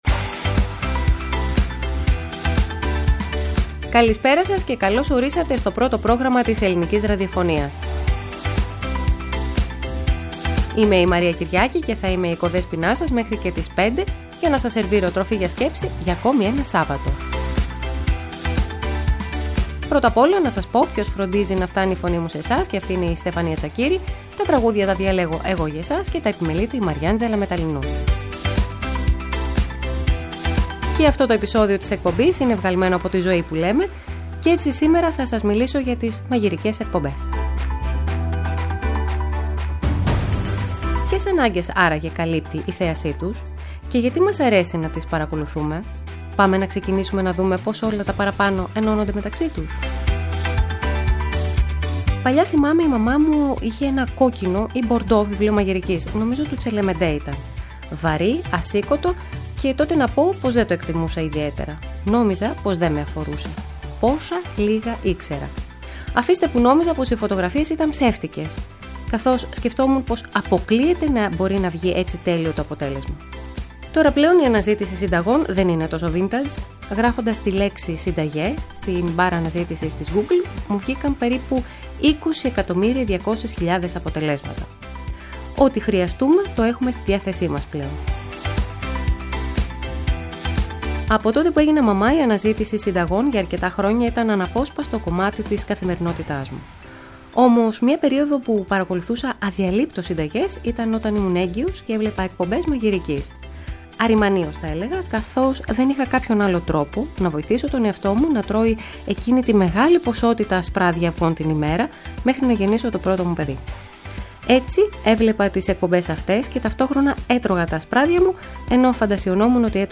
Άνθρωποι που αγαπούν το φαγητό, διάσημοι και βραβευμένοι σεφ συμμετέχουν στην εκπομπή για να μοιραστούν την τεχνογνωσία τους και τις ιδέες τους για μια καλύτερη ζωή.